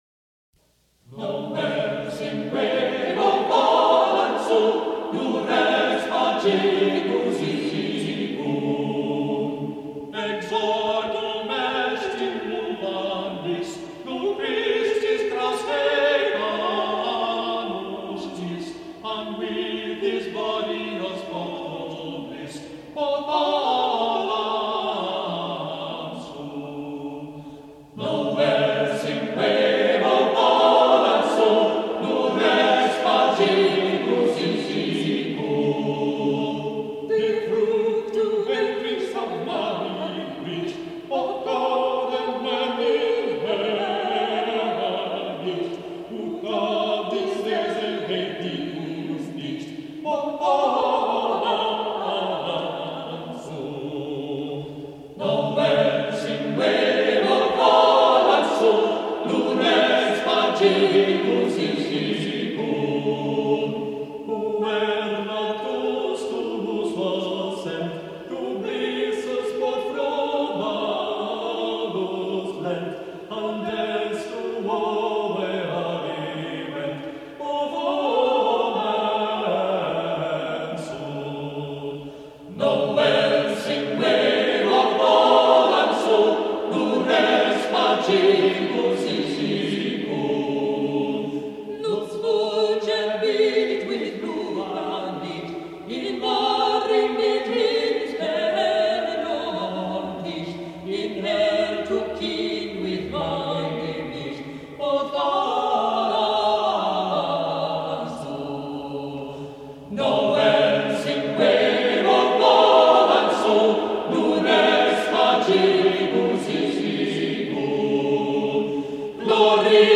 This rendering is from the recording session for the Musical Heritage Society LP record released in 1986.
| Vocal Ensemble 'Nowell' session 1986